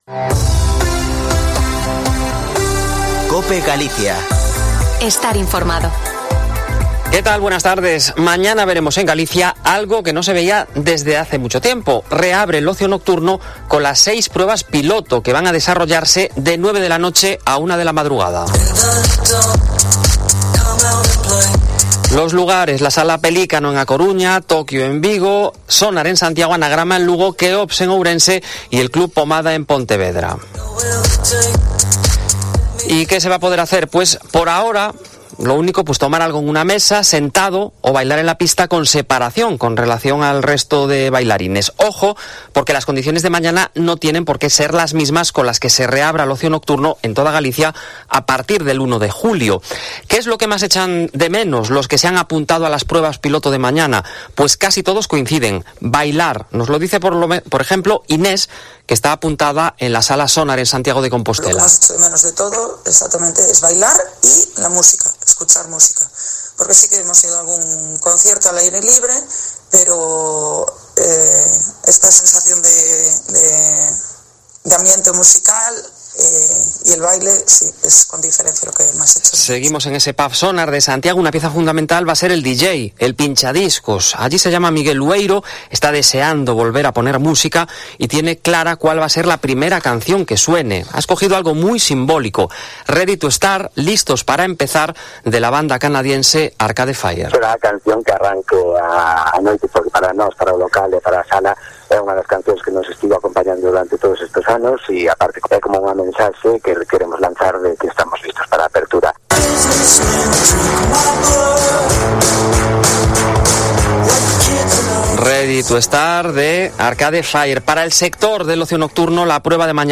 Informativo Mediodia en Cope Galicia 11/06/2021. De 14.48 a 14.58h